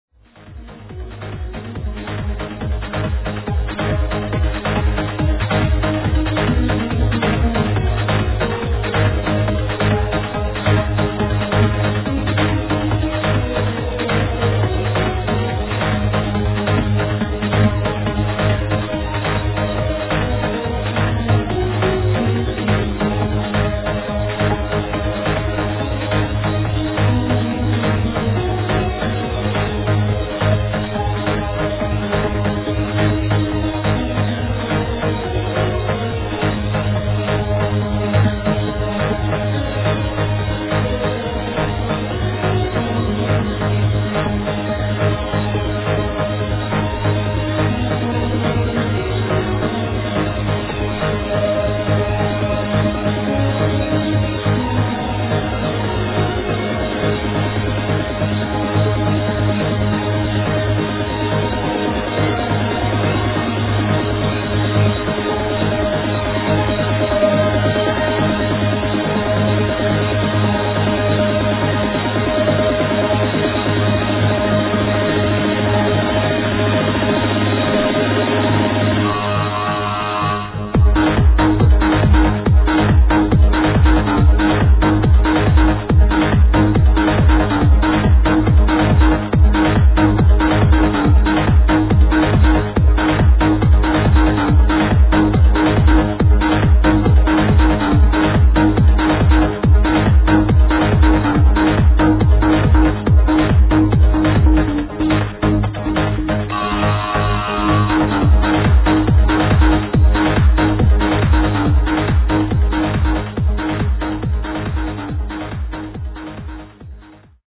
Держит в "пред"чувствии выброса энергии.
Таковы свойства качественного trance жанра)